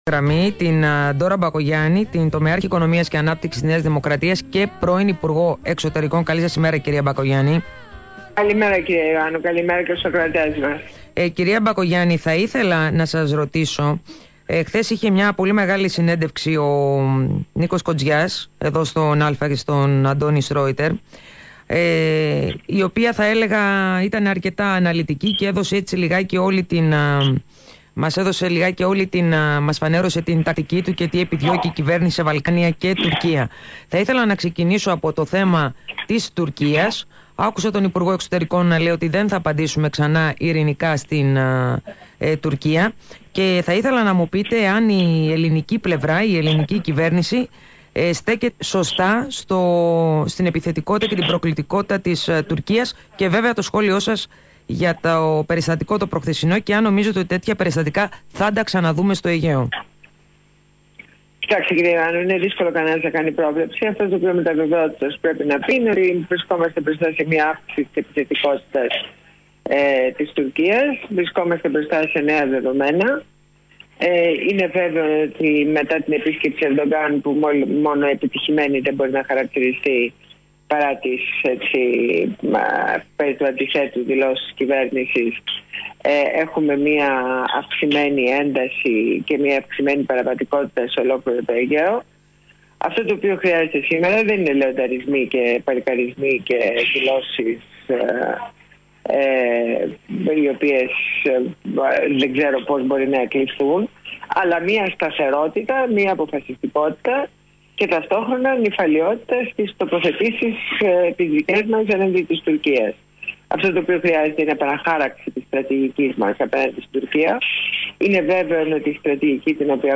Συνέντευξη στο ραδιόφωνο του ALPHA